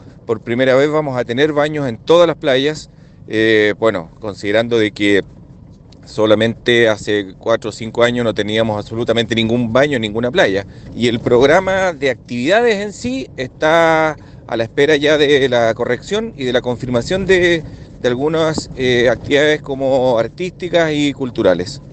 El alcalde, Miguel Meza, indicó que por primera vez instalarán baños públicos en todas las playas, mientras que -en paralelo- preparan la parrilla artística.